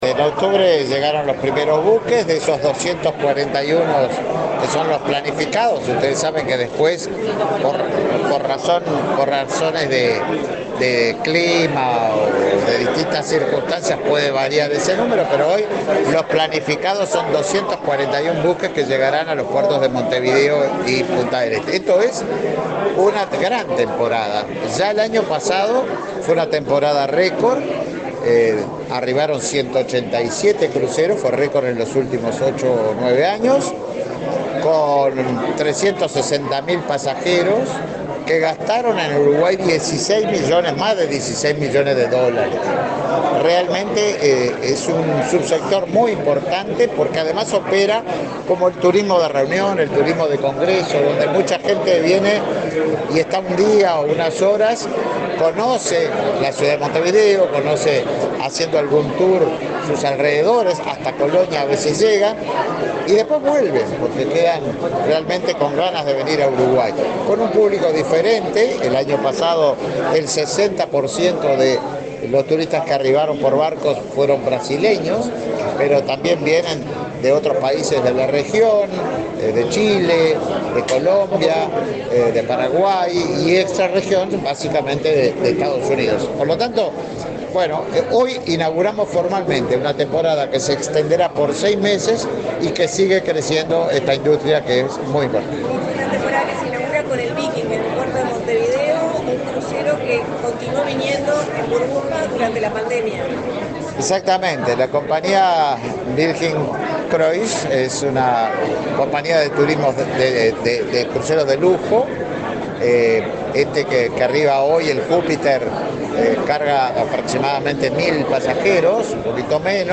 Declaraciones del ministro de Turismo, Tabaré Viera
Declaraciones del ministro de Turismo, Tabaré Viera 04/12/2023 Compartir Facebook X Copiar enlace WhatsApp LinkedIn Este lunes 4 en Montevideo, el presidente de la Administración Nacional de Puertos, Juan Curbelo, y el ministro de Turismo, Tabaré Viera, participaron del acto de lanzamiento de la temporada de cruceros 2023-2024. Luego, el secretario de Estado dialogó con la prensa.